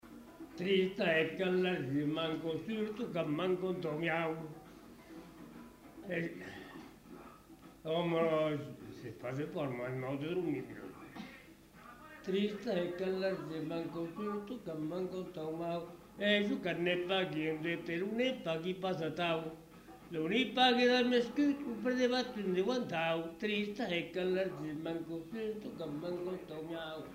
Aire culturelle : Savès
Lieu : Pavie
Genre : chant
Effectif : 1
Type de voix : voix d'homme
Production du son : chanté
Danse : rondeau